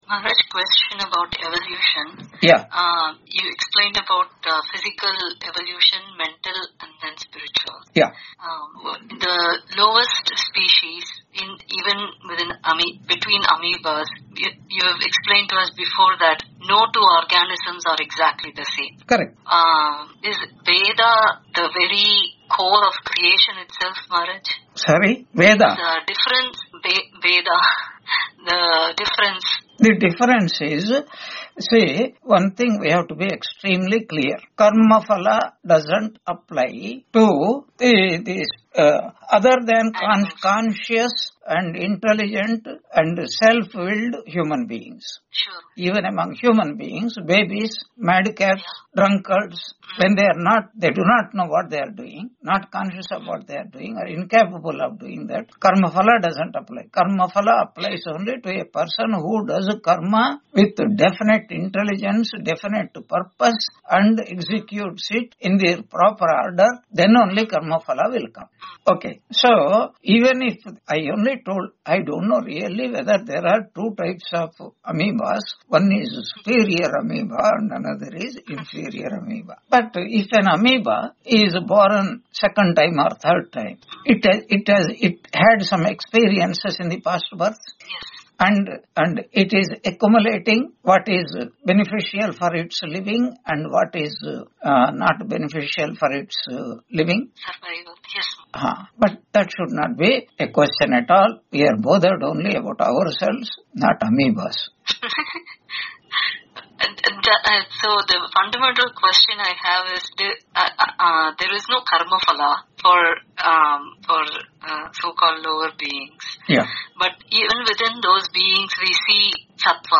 Chandogya Upanishad 5.4 Introduction Lecture 163 on 13 December 2025 Q&A - Wiki Vedanta